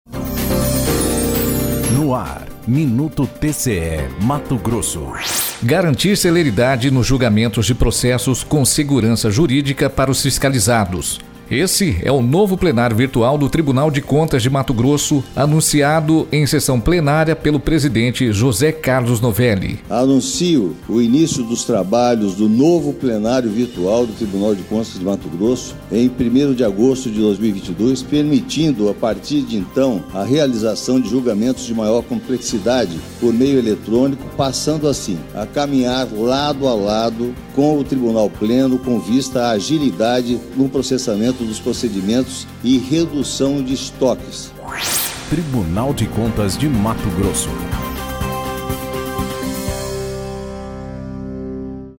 Sonora: José Carlos Novelli – conselheiro presidente do TCE-MT